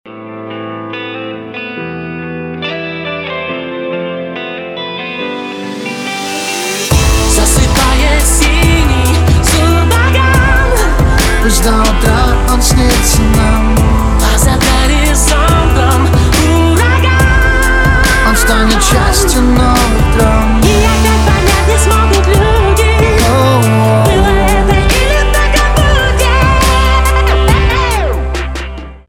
• Качество: 320, Stereo
поп
громкие
дуэт
эстрадные